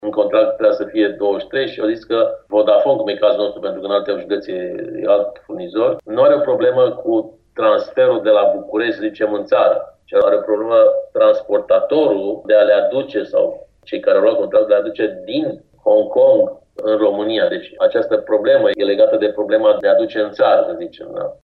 Lotul de tablet este în întârziere, spune inspectorul general, Marin Popescu, din cauza unor probleme cu furnizorul  de servicii de internet, iar aparatele nu sunt încă în ţară.